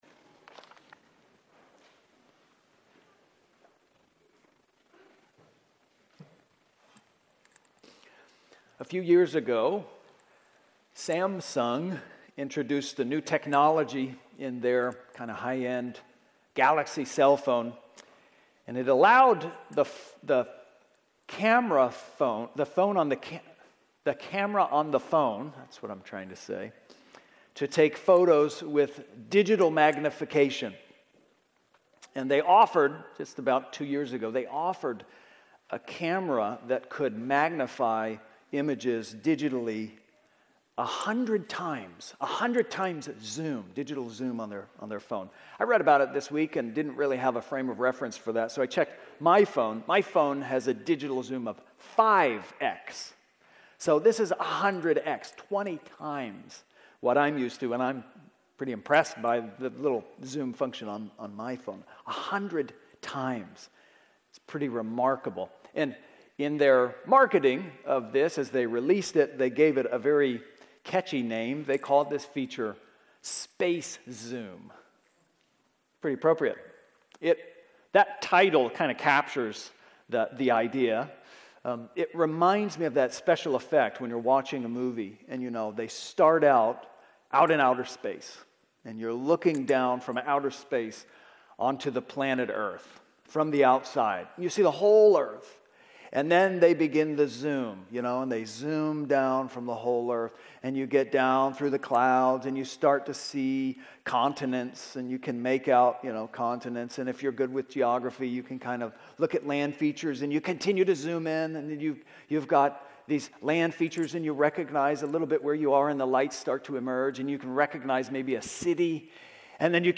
Partial recording - the recording ran out of batteries during the sermon.